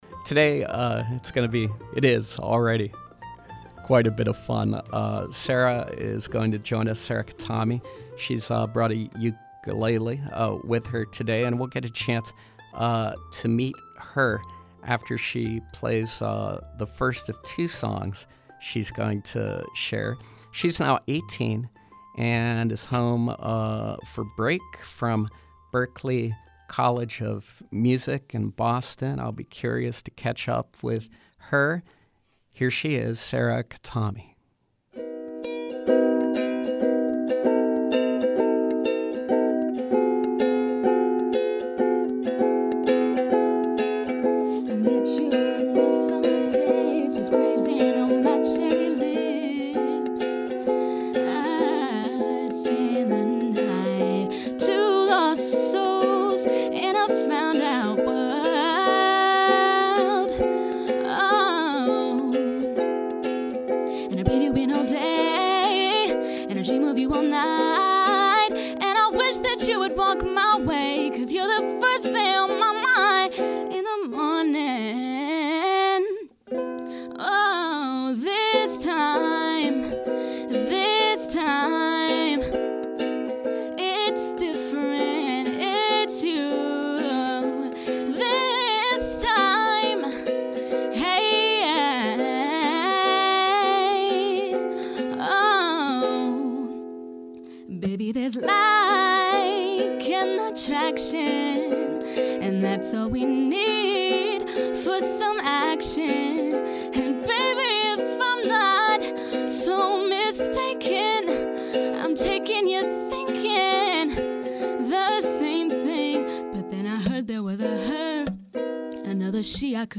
appears on SLB again to perform on voice and ukulele and to talk about her college experience.